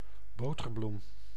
Ääntäminen
IPA: /ˈboːtərblum/